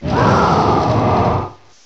pokeemerald / sound / direct_sound_samples / cries / centiskorch.aif